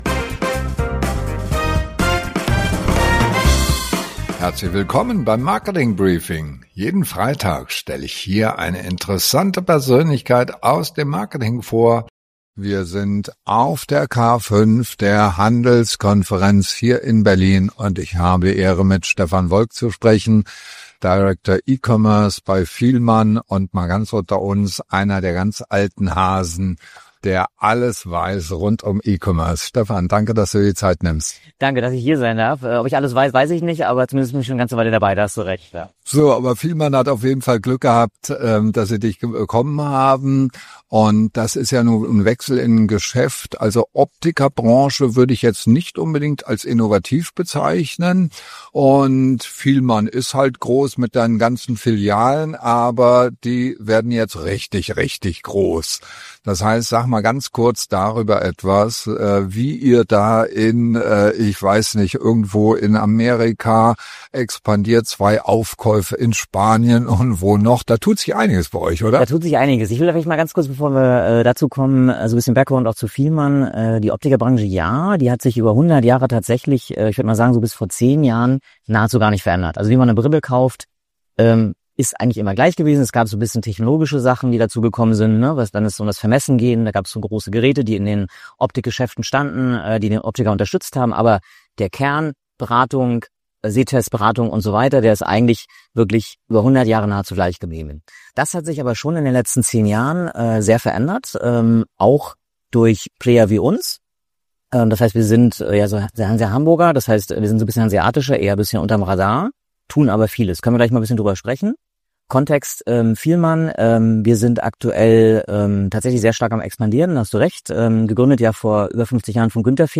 Jeden Freitag Interviews mit spannenden Persönlichkeiten aus der Digital- & Marketing-Szene